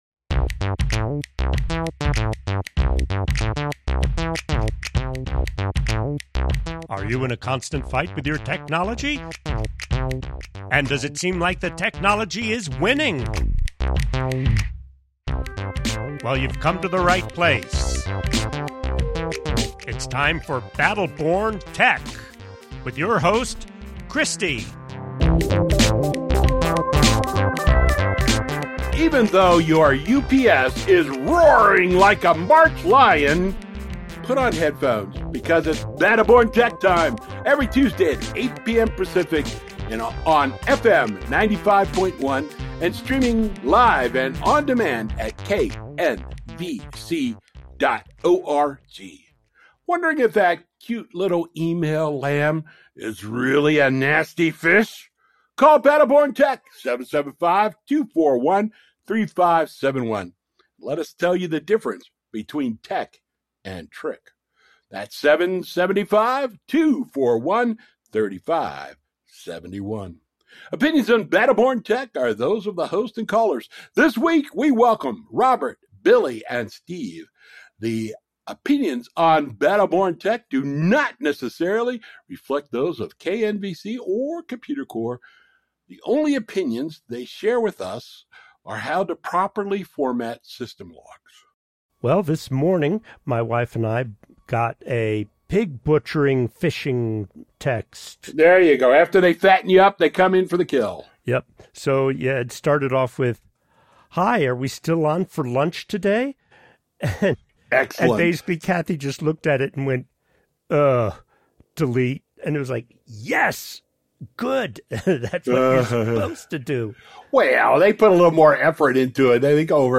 welcome three callers with three very different tech puzzles.